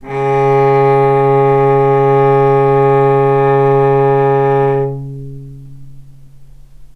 healing-soundscapes/Sound Banks/HSS_OP_Pack/Strings/cello/ord/vc-C#3-mf.AIF at cc6ab30615e60d4e43e538d957f445ea33b7fdfc
vc-C#3-mf.AIF